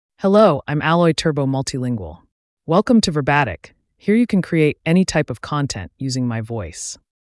MaleEnglish (United States)
Alloy Turbo Multilingual is a male AI voice for English (United States).
Voice sample
Listen to Alloy Turbo Multilingual's male English voice.
Alloy Turbo Multilingual delivers clear pronunciation with authentic United States English intonation, making your content sound professionally produced.